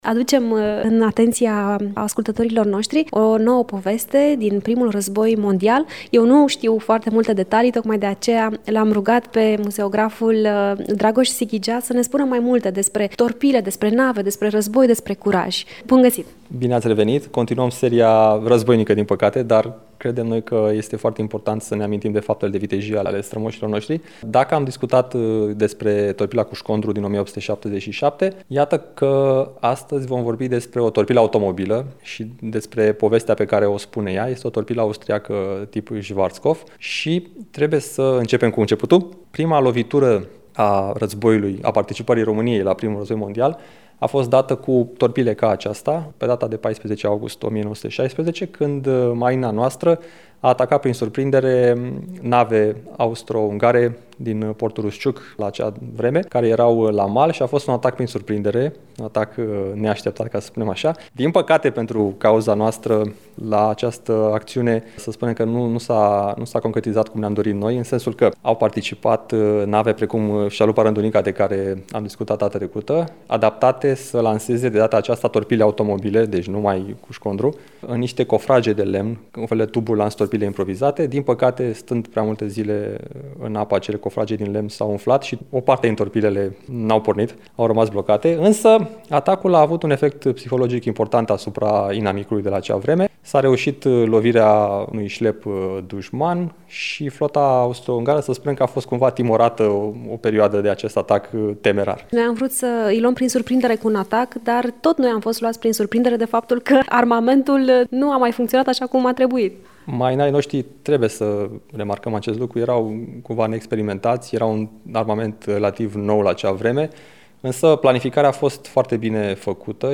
Muzeograful